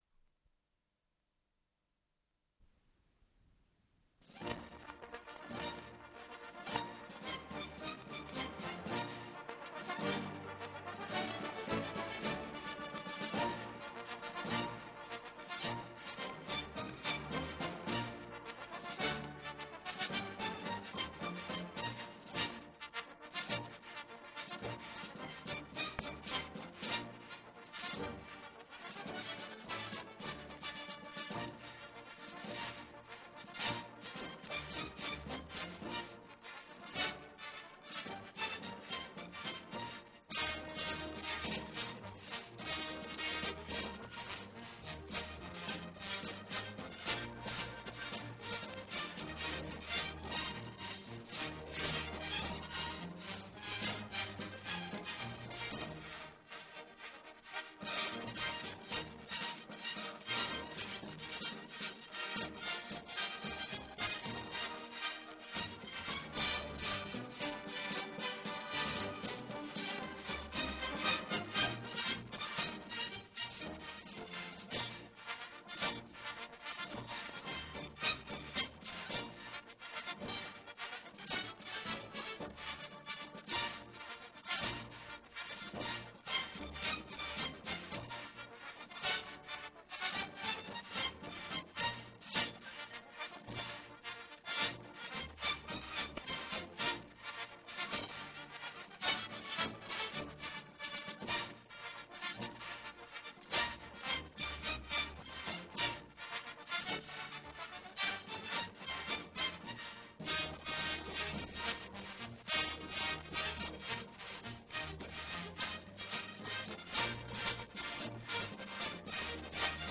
Запись 1971 год Дубль моно.